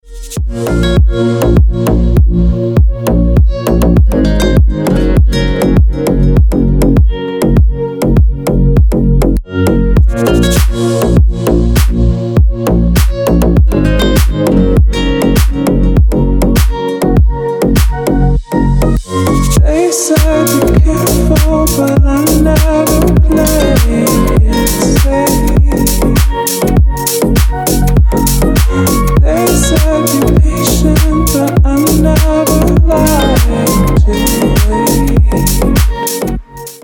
Мелодичная новинка под рингтон